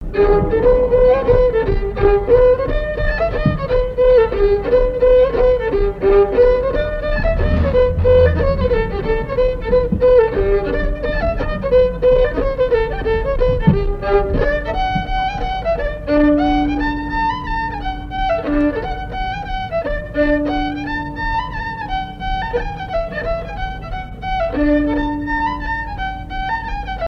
danse : mazurka
Assises du Folklore
Pièce musicale inédite